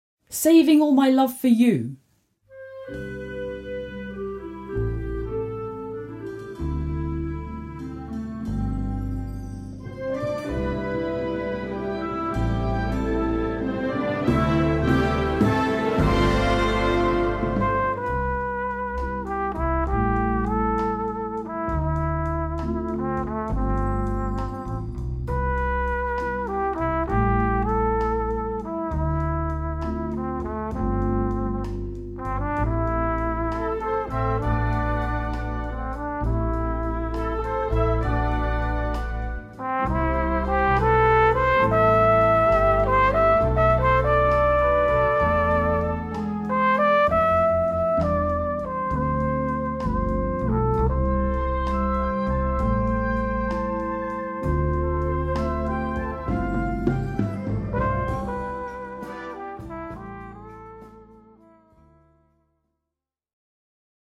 Solo für Flügelhorn/Trompete und Blasorchester